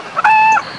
Heron Sound Effect
heron.mp3